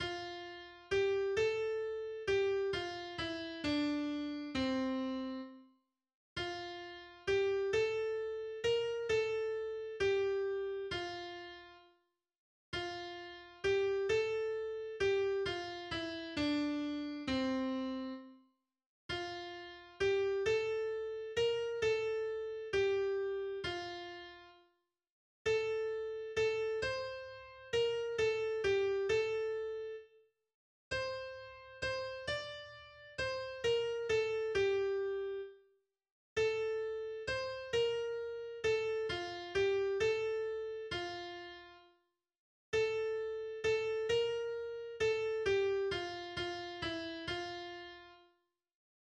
Tonsatz